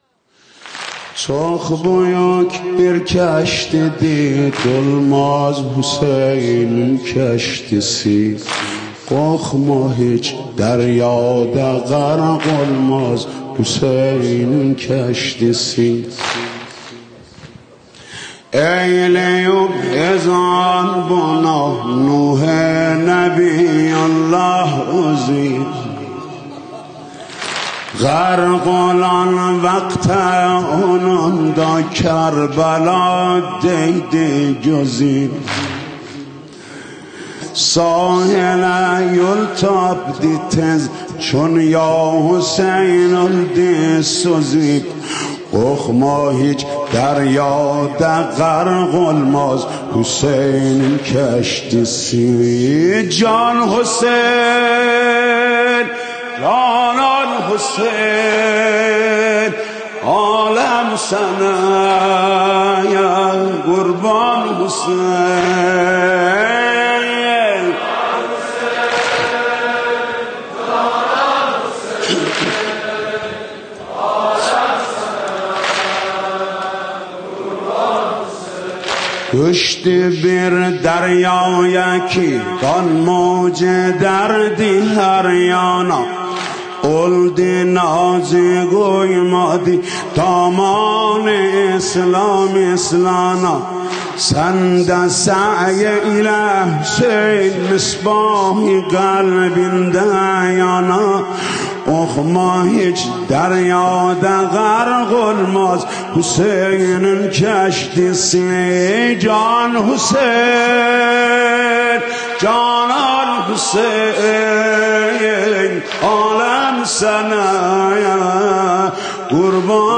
رجز ترکی